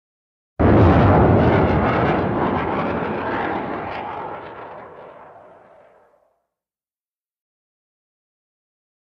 WEAPONS - VARIOUS SURFACE TO AIR MISSILE: EXT: Launch and rocket trailing, distant.